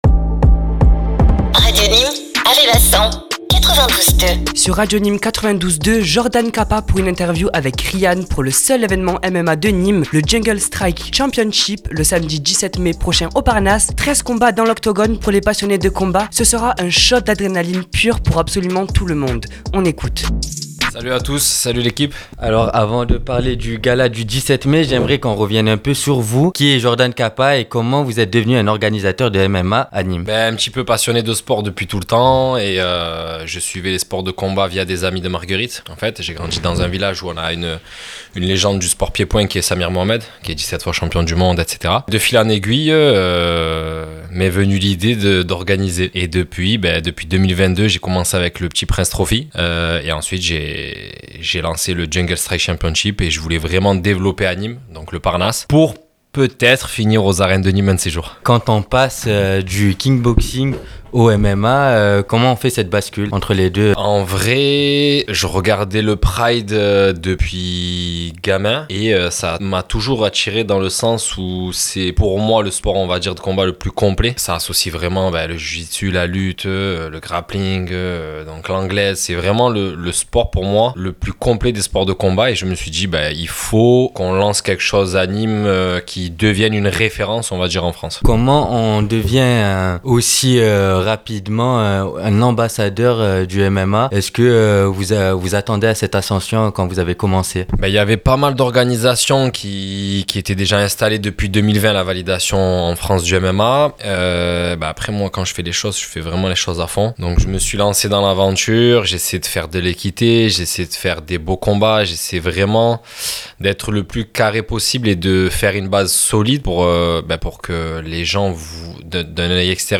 INTERVIEW JUNGLE STRIKE CHAMPIONSHIP